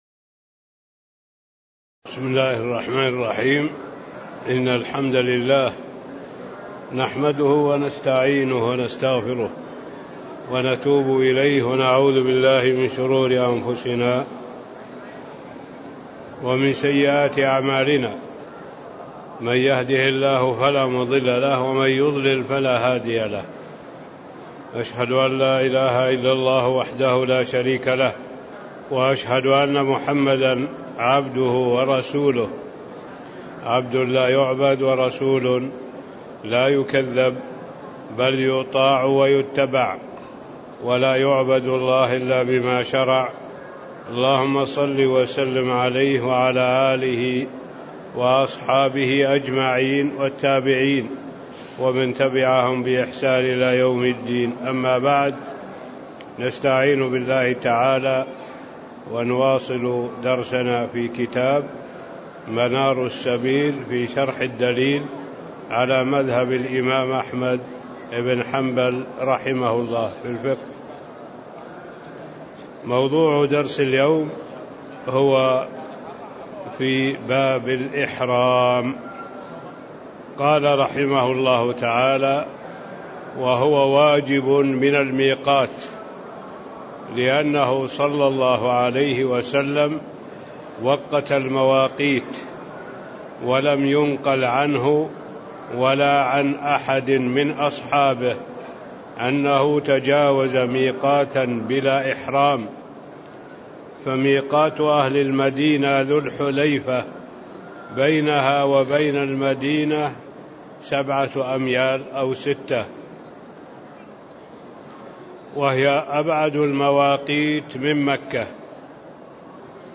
تاريخ النشر ١٨ شوال ١٤٣٦ هـ المكان: المسجد النبوي الشيخ